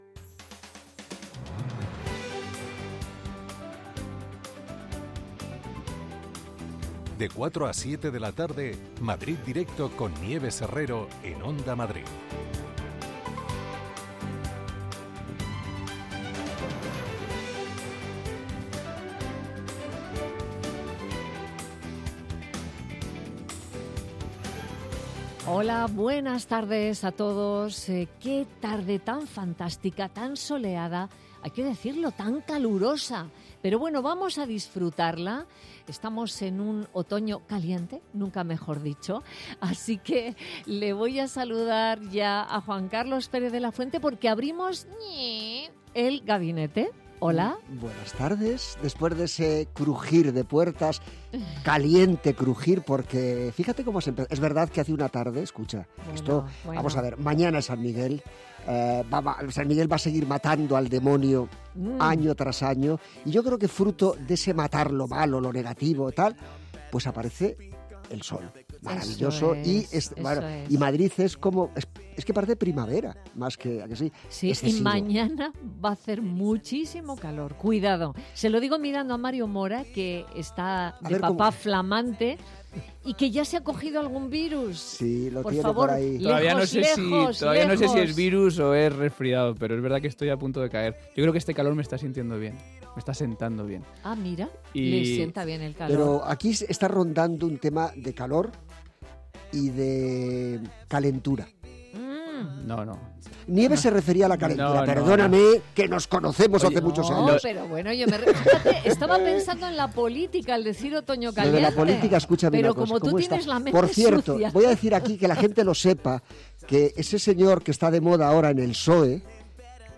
Tres horas de radio donde todo tiene cabida. La primera hora está dedicada al análisis de la actualidad en clave de tertulia. La segunda hora está dedicada a la cultura en Madrid.